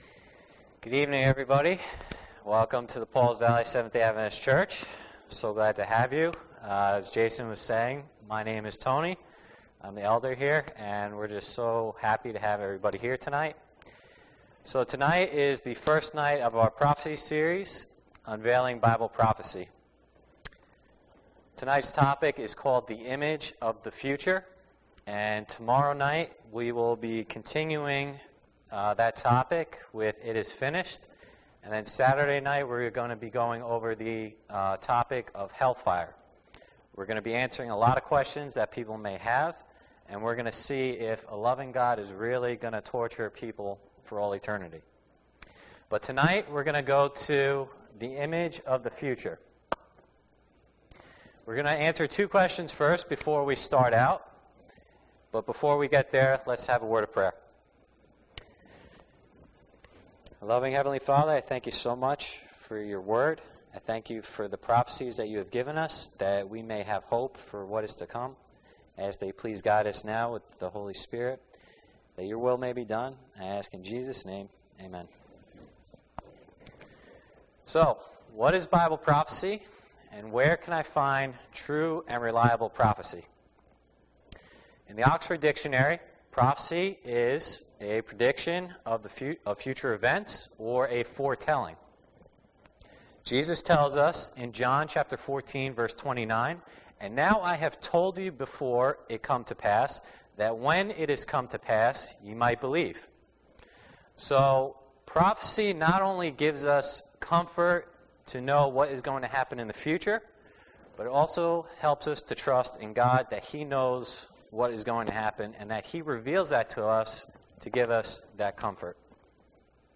This is the first sermon of the Unveiling Bible Prophecy Seminar.